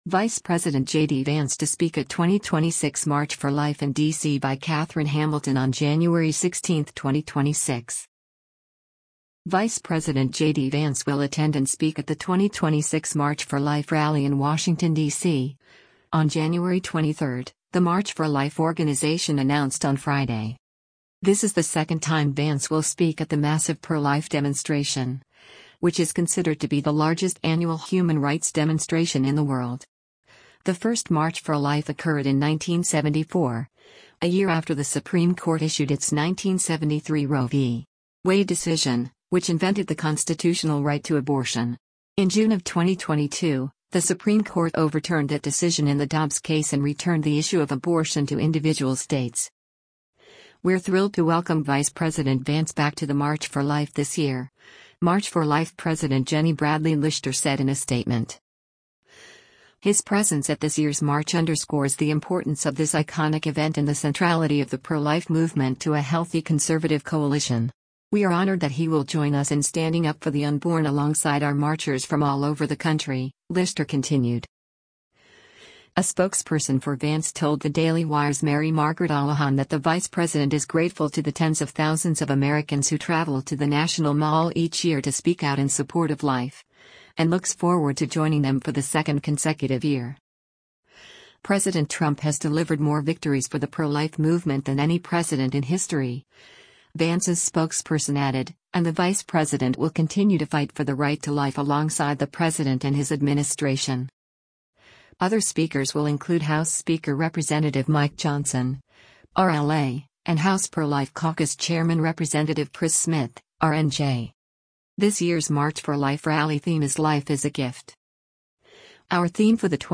Vice President JD Vance speaks to antiabortion demonstrators during the 52nd annual Nation